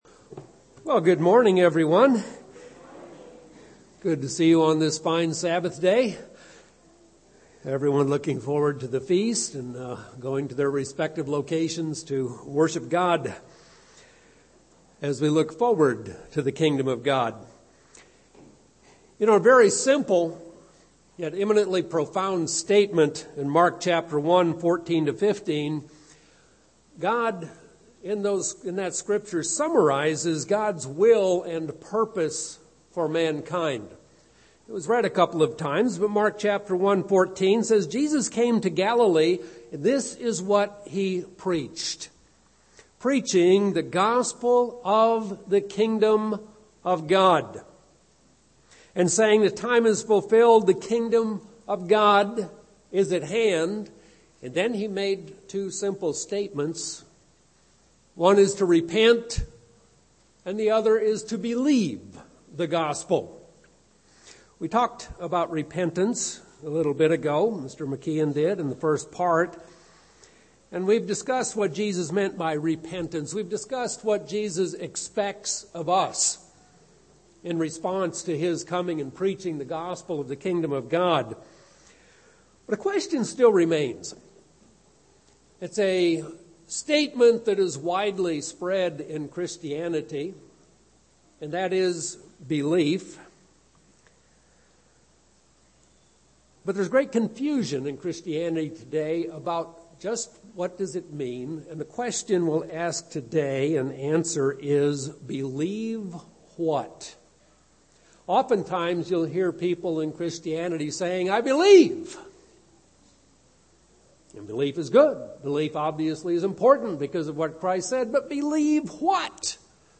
What does Scripture really have to say about being saved? Learn more in this Kingdom of God seminar.